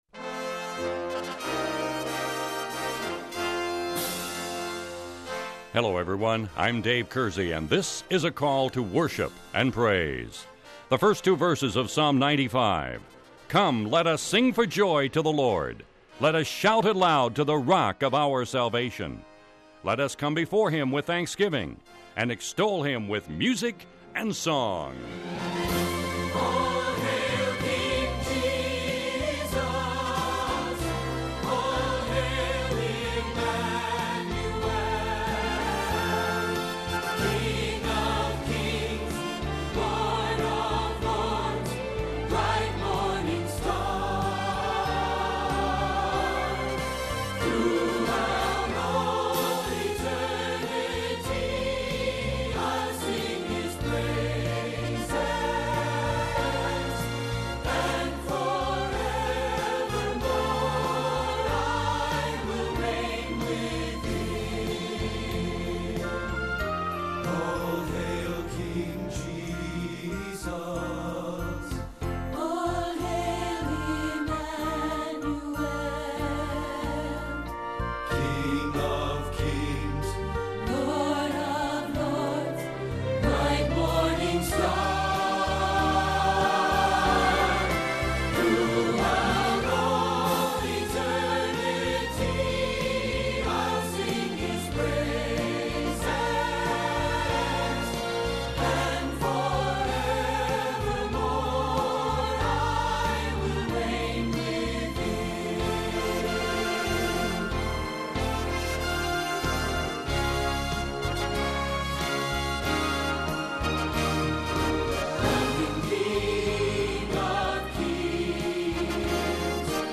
This week’s Devotional Topic On this week’s Call To Worship program we will be singing songs and sharing thoughts on “The Fragrance of Christ” and how we should permit those that we come in contact with to become aware of the presence of Jesus, our Savior. We can communicate the presence of the Savior because it is the Lord Himself who lives within.